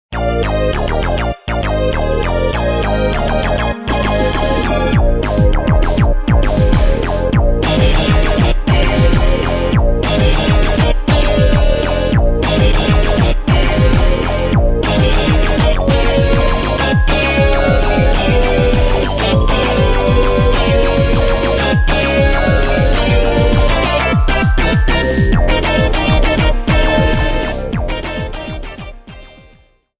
Background music
Reduced length to 30 seconds, with fadeout.